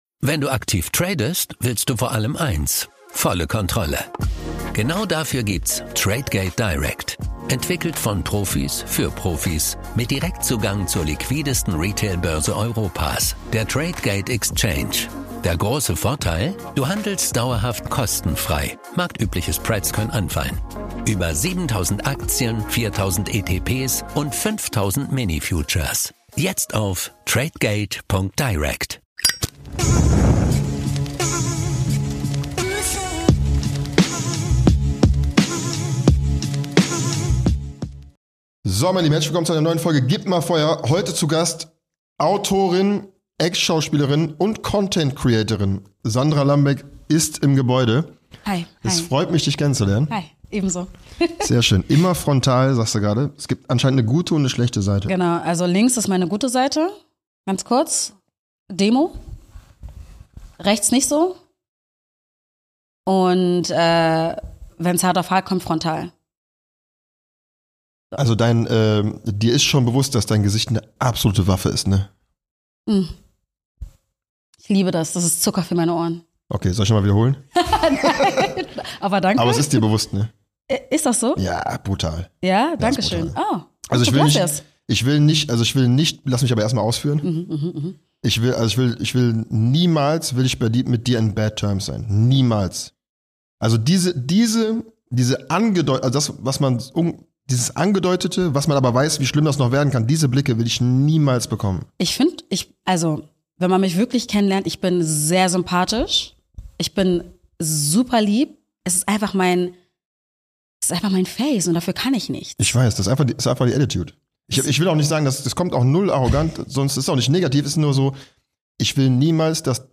Beschreibung vor 3 Monaten Social-Media-Star und Autorin Sandra Lambeck war zu Gast und hat sich von ihrer deepen Seite gezeigt. Es wurde natürlich auch viel gelacht, diskutiert und philosophiert über aktuelle, zwischenmenschliche Probleme und persönliche Highlights.